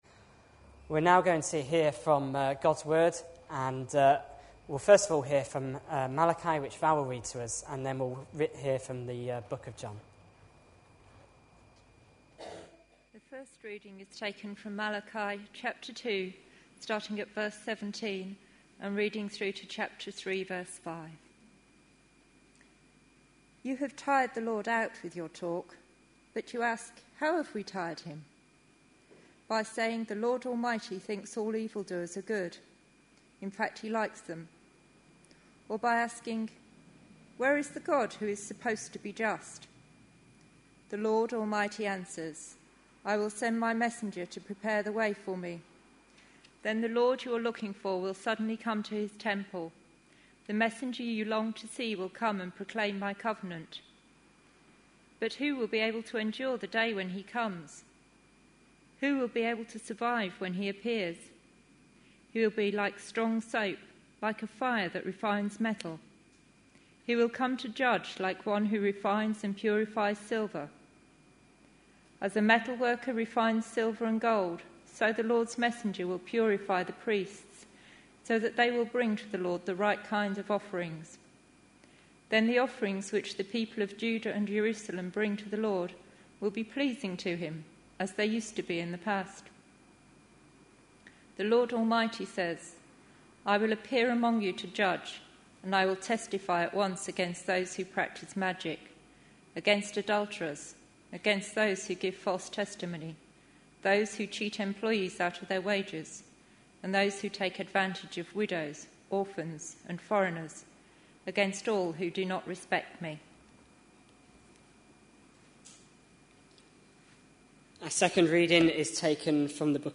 A sermon preached on 30th October, 2011, as part of our Malachi (Sunday evenings). series.
The sermon is followed by several questions sent in by text.